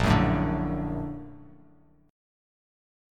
Bb+M7 chord